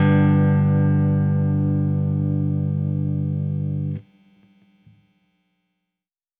Weathered Guitar Outro.wav